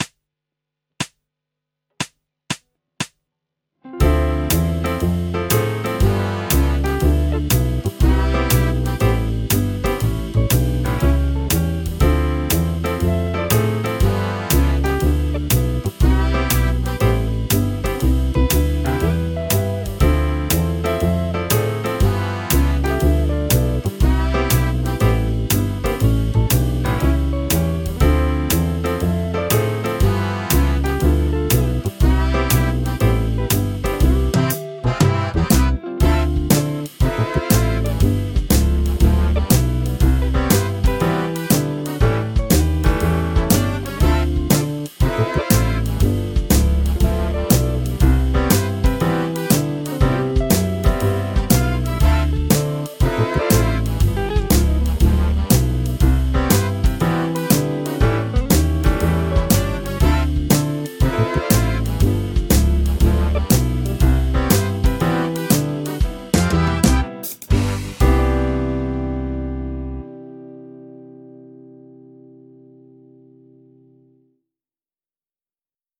オルタード・ドミナント7thスケール ギタースケールハンドブック -島村楽器